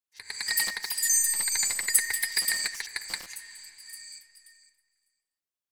夏に連想する音_3